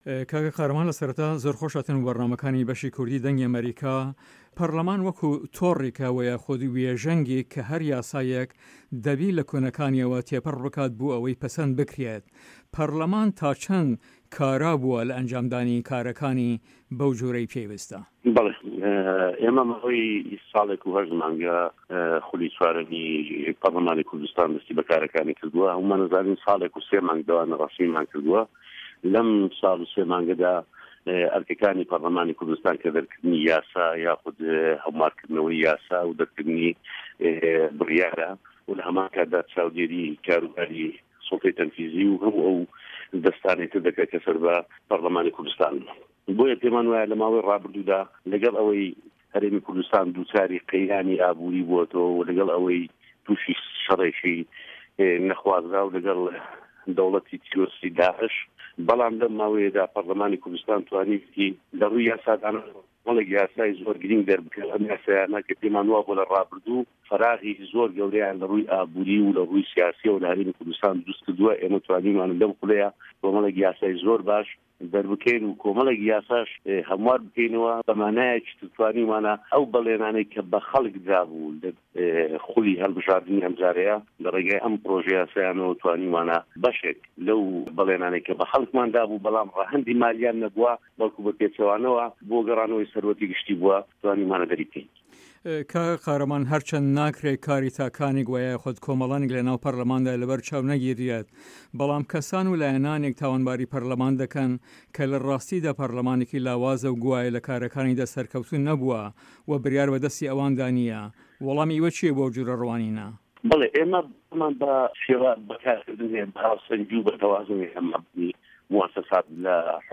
قاره‌مان قادر سه‌رۆکی لیژنه‌ی کاروباره‌کانی په‌رله‌مانی هه‌رێمی کوردستان له‌سه‌ر فراکسیۆنی گۆڕان له‌ هه‌ڤپه‌یڤینێکدا له‌گه‌ڵ به‌شی کوردی ده‌نگی ئه‌مه‌ریکا ده‌ڵێت " له‌گه‌ڵ ئه‌وه‌ی له‌ ماوه‌ی رابوردووا هه‌رێمی کوردستان دووچاری قه‌یرانی ئابوری بوه‌ته‌وه‌ و تووشی شه‌رێکی نه‌خوازراوو له‌گه‌ڵ ده‌وڵه‌تی داعش، به‌ڵام له‌م ماوه‌یه‌دا په‌رله‌مانی کوردستان له‌ رووی یاسا دانانه‌وه‌ توانیوه‌تی کۆمه‌ڵێک یاسای زۆر گرنگ ده‌ر بکات ، که‌ ئه‌م یاسایانه‌ پێیمان وا بوه‌ له‌ رابوردوو بۆشاییه‌کی زۆر گه‌وره‌ له‌ رووی ئابوری و له‌ رووی سیاسیه‌وه‌ له‌ هه‌رێمی کوردستان درووست کردووه‌، ئێمه‌ توانیومانه‌ له‌م‌ خوله‌دا کۆمه‌ڵێک یاسای زۆر باش ده‌ر بکه‌ین و کۆمه‌ڵێک یاساش هه‌موار بکه‌ینه‌وه‌، به‌و واتاییه‌ی ئه‌و به‌ڵێنانه‌ی به‌ خه‌ڵک درابوو له‌ خولی هه‌ڵبژاردنی ئه‌م جاره‌یه‌ له‌ رێگای ئه‌م پرۆژه‌ یاسانه‌وه‌ توانیومانه‌ به‌شێک له‌و به‌ڵێنانه‌ی که‌ به‌ خه‌ڵکمان دابوو به‌ڵام ره‌هه‌ندی داراییان نه‌بوه‌ به‌ڵکۆ به‌ پێچه‌وانه‌وه‌ بۆ گه‌رانه‌وه‌ی سامانی گشتی بوه،‌ توانیومانه‌ ده‌ری بکه‌ین.کاک قادر هه‌ر وه‌ها ده‌ڵێت پێداچوونه‌وه‌یه‌کی گشتیمان ‌ به‌ هه‌موو ئه‌و یاسایانه‌دا کردووه که‌ ره‌هه‌ندی نیشتمانیان هه‌یه‌، وه‌ کۆمه‌ڵێک یاساش که‌ ره‌هه‌ندی داراییان هه‌بوه‌، واته‌ له‌ رابوردوودا ‌ سامانی ئه‌م میلله‌ته‌ی ‌ له‌ناو داوه‌، ئێمه‌ له‌م خوله‌ی په‌رله‌مان توانیمانه‌ هه‌مواری بکه‌ینه‌وه‌ یاخود یاسای بۆ ده‌رکه‌ین.